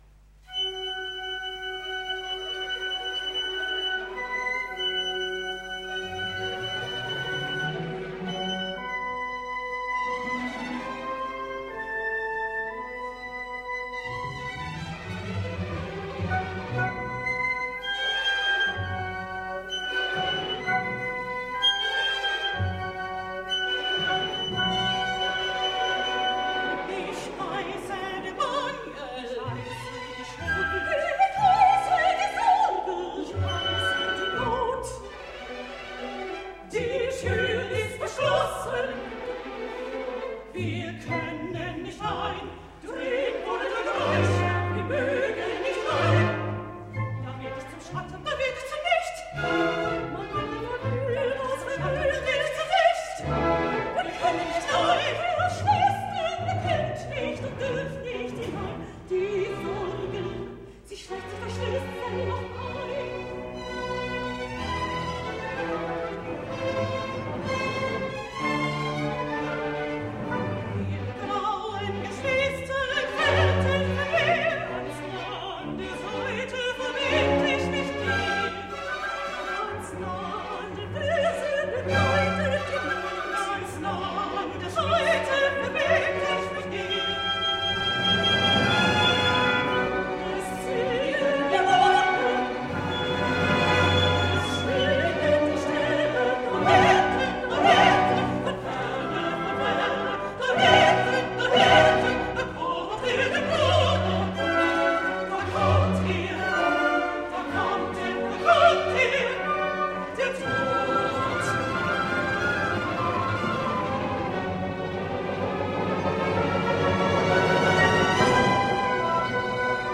Sinfonie_-_Zwickau_Schumann-Faust_pt1.mp3